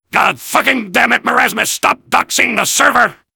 soldier_jeers06.mp3